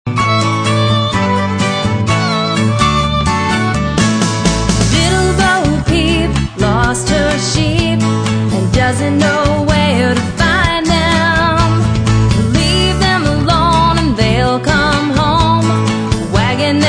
Nursery Rhyme